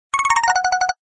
SAccessGranted.ogg